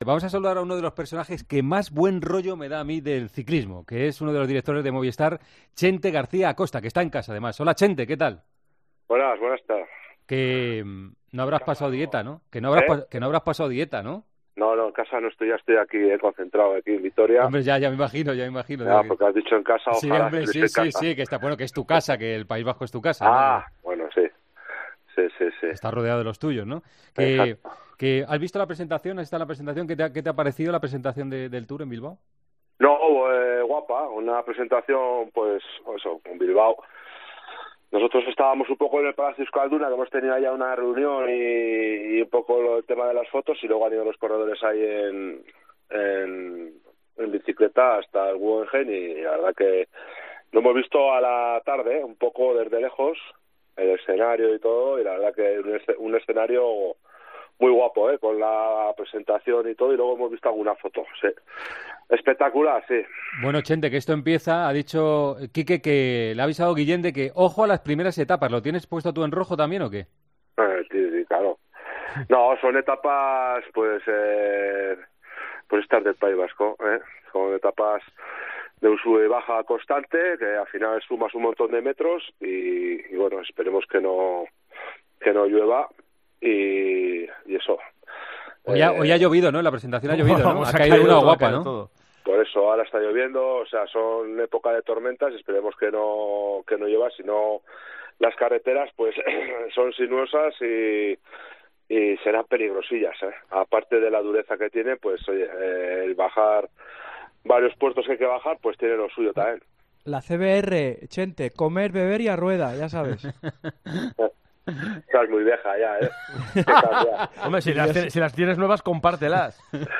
AUDIO: El director deportivo de Movistar repasa en El Partidazo de COPE el inicio del Tour de Francia, que arranca este próximo sábado desde Bilbao.